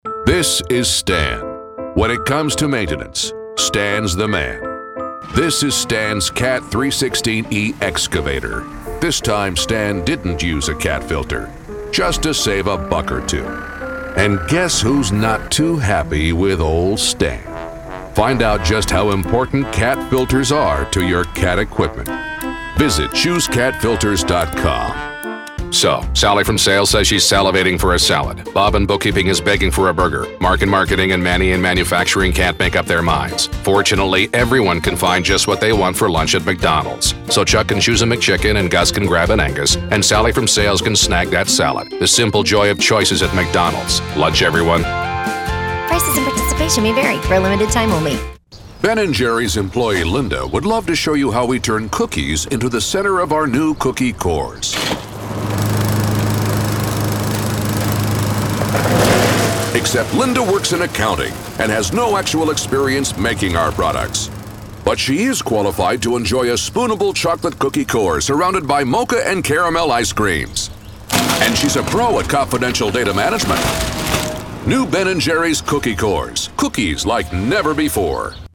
Iconic, Dramatic, Impeccable.
Deadpan Humor